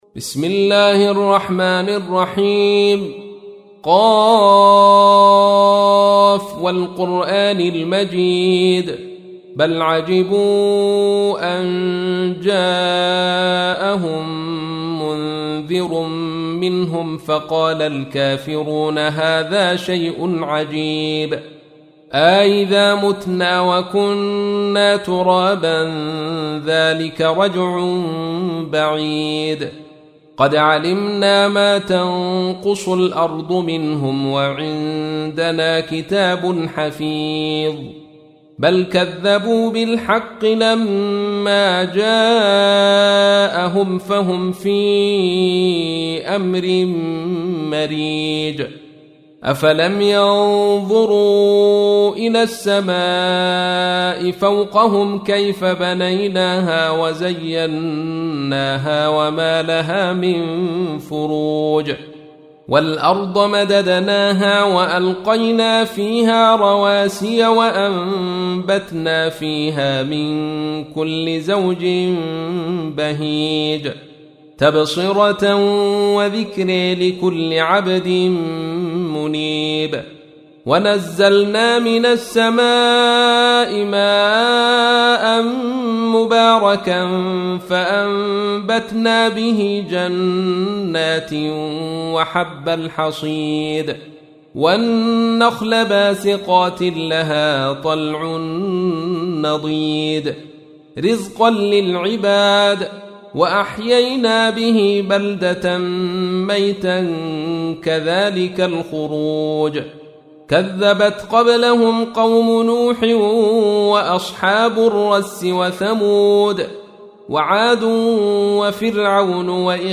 تحميل : 50. سورة ق / القارئ عبد الرشيد صوفي / القرآن الكريم / موقع يا حسين